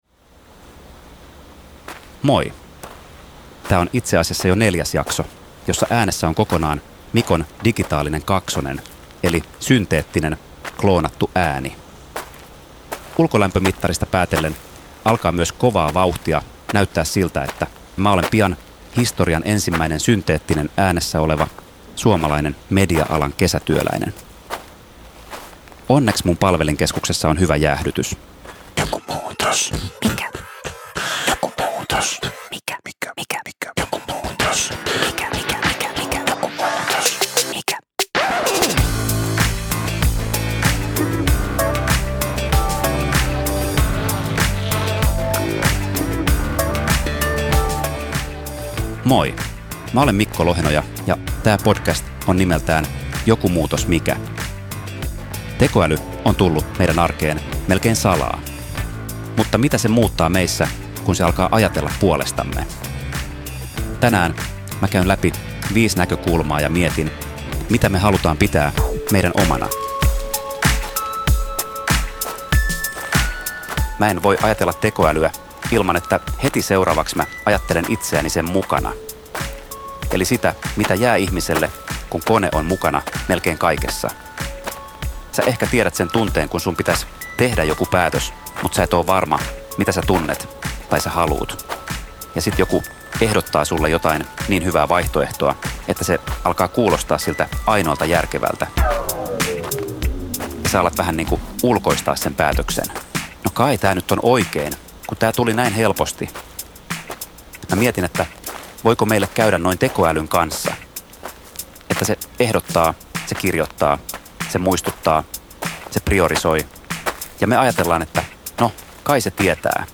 Kuuntele jakso Tässä jaksossa äänessä on tekijän oma ääniklooni , synteettisesti tuotettu versio hänen puheestaan.